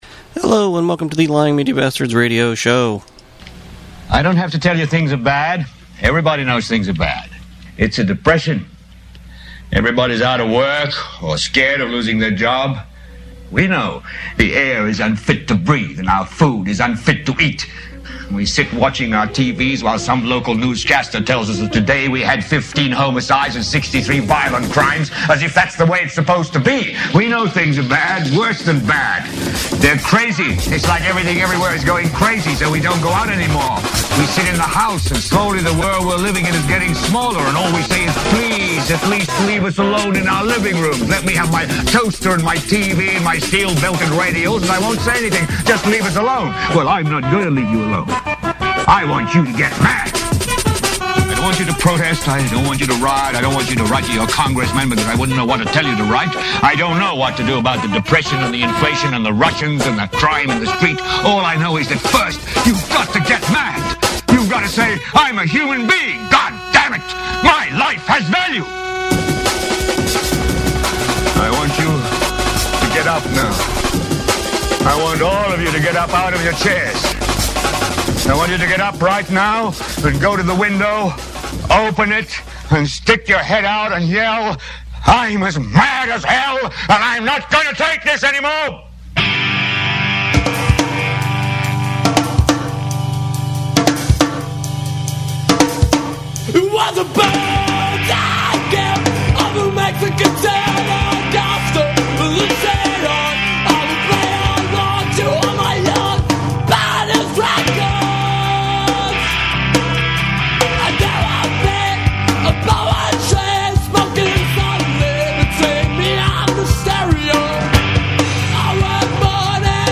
News, politics, rock, inappropriate circus music.